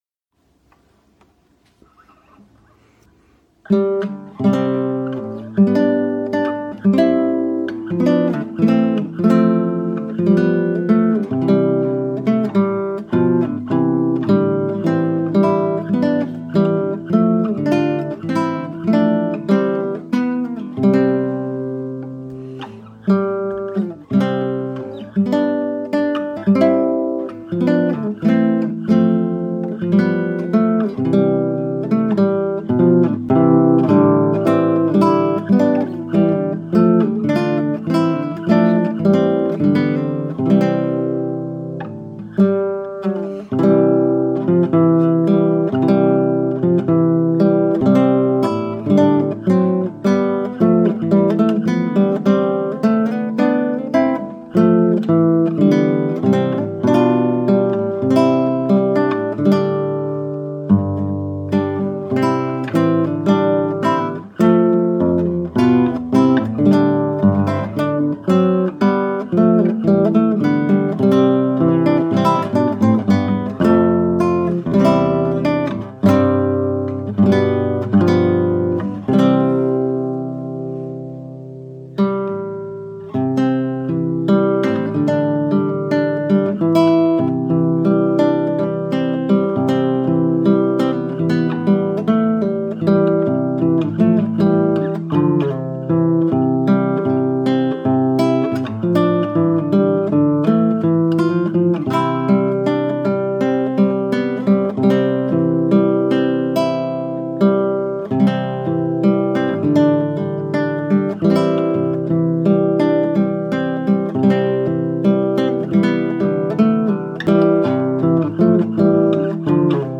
A classical guitar setting for "The Spirit of God." True to my style, all 6 original verses are included, and hey, why not add a refrain for good measure.
Voicing/Instrumentation: Guitar , Guitar Chords Available We also have other 36 arrangements of " The Spirit of God ".